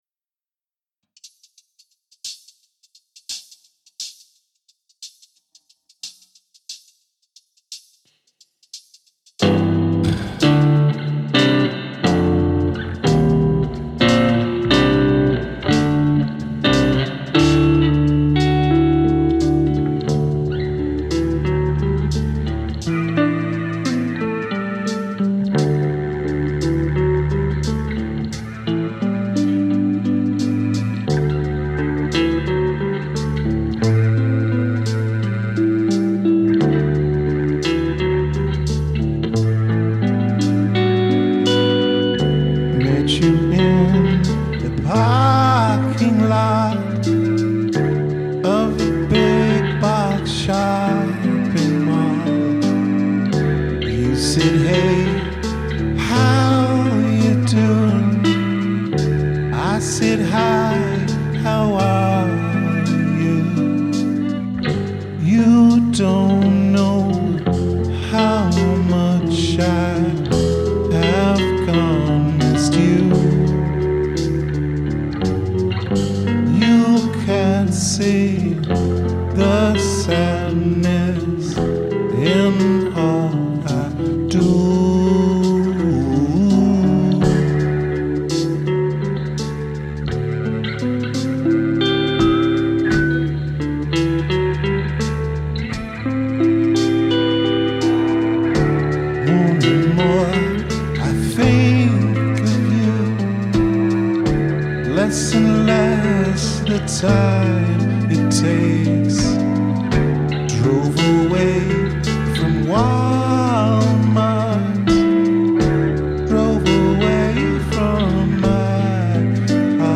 piano
as usual these were rehearsal takes with lots of problems but moving on 🙂
The Available
Rehearsal recordings